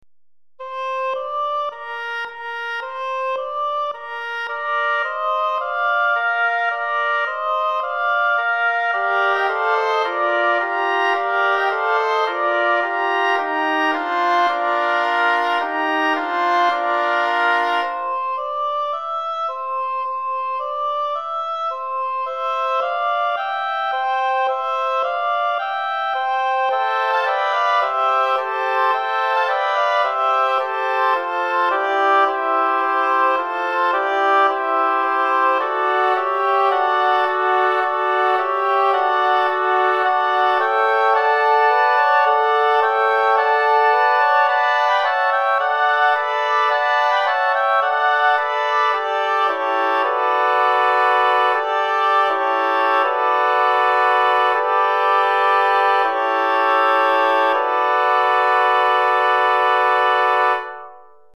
4 Hautbois